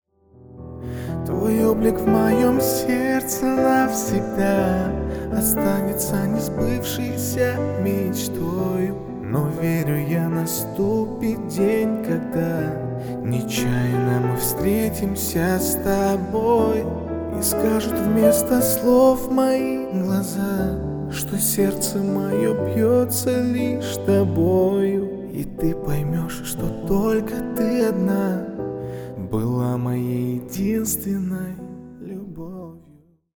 Поп Музыка
спокойные # тихие
грустные